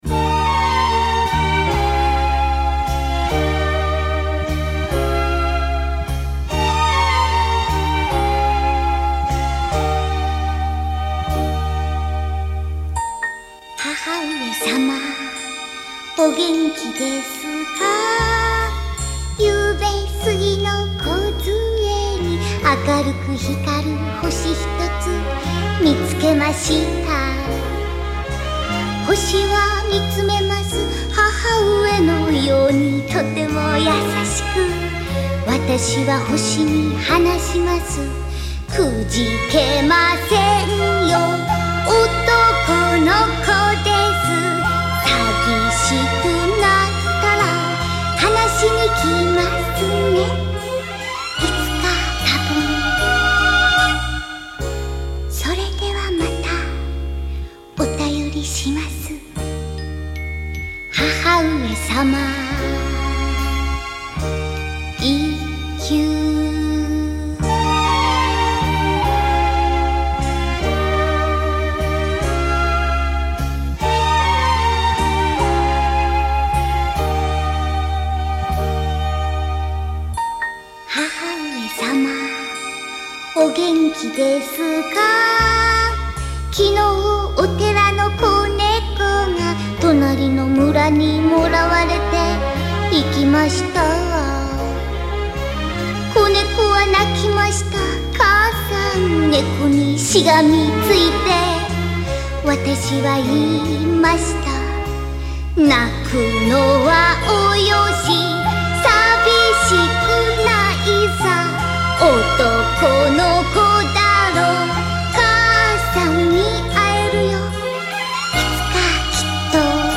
立体声320kmp3